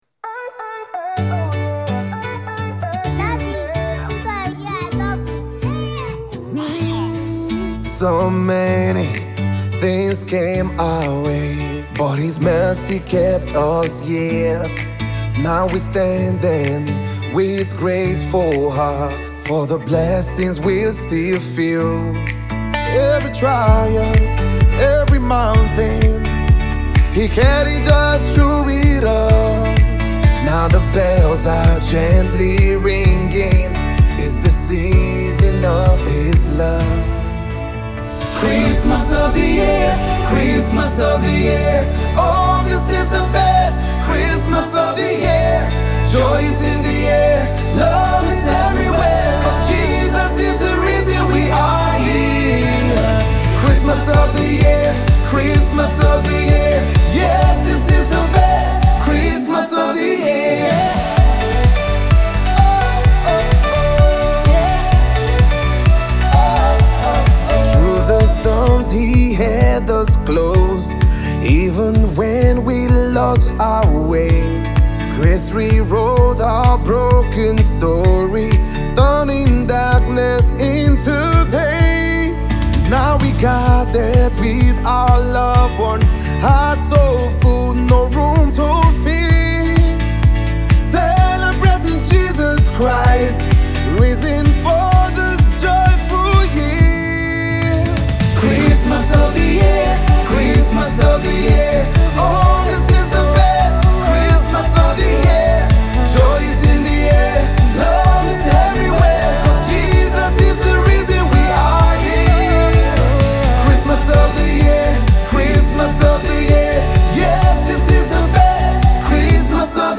is a warm, uplifting Christmas single
Blending heartfelt lyrics with rich melodies
carries a polished, vibrant sound
memorable chorus